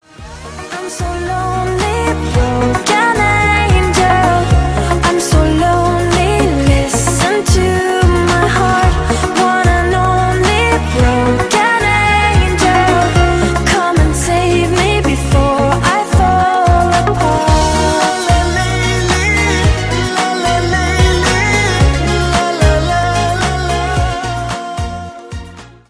• Качество: 128, Stereo
поп
дуэт
романтичные
красивый женский голос